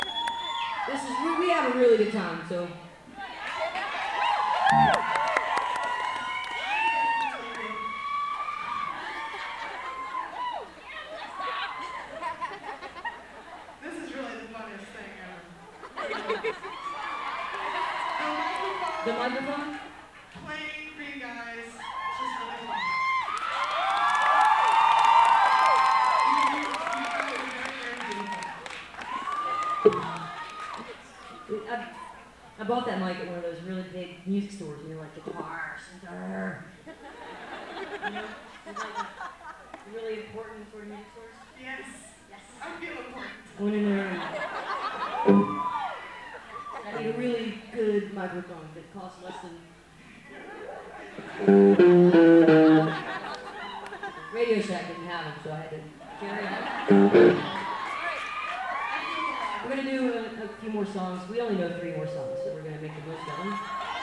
lifeblood: bootlegs: 2001-04-04: 9:30 club - washington, d.c. (amy ray and the butchies)
13. talking with the crowd (1:04)